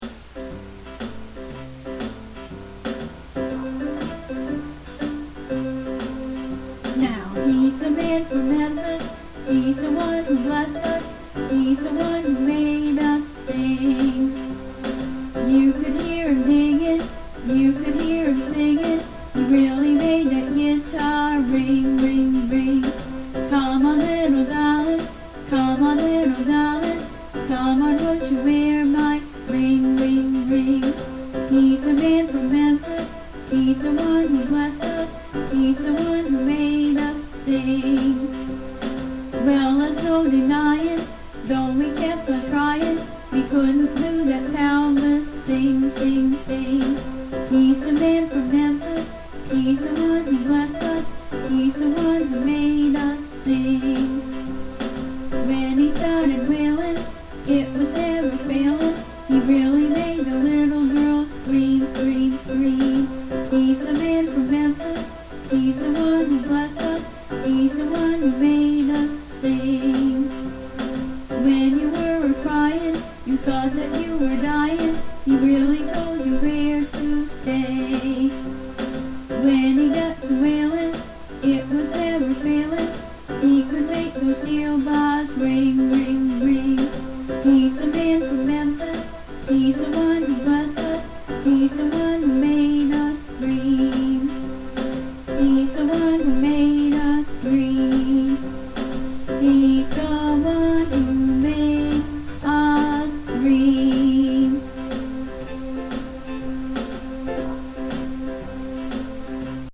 Accompany Herself On Her Keyboard